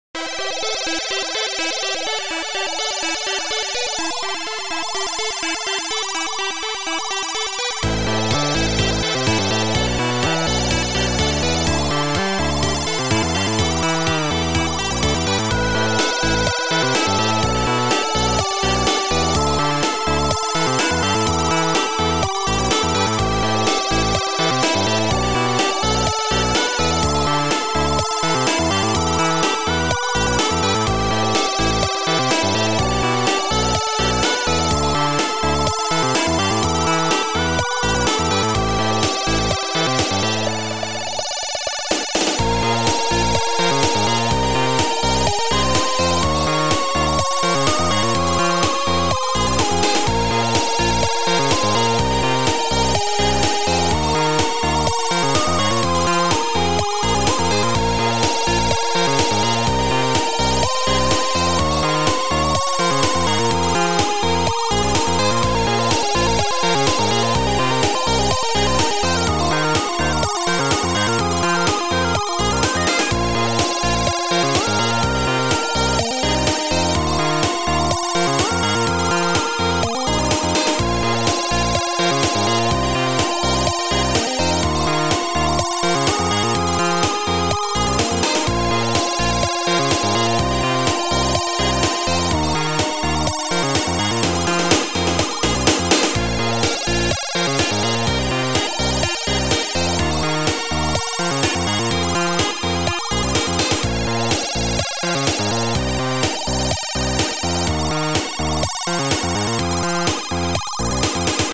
chip intro tune-#3.mod